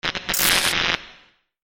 دانلود آهنگ رادیو 23 از افکت صوتی اشیاء
دانلود صدای رادیو 23 از ساعد نیوز با لینک مستقیم و کیفیت بالا
جلوه های صوتی